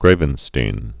(grāvən-stēn)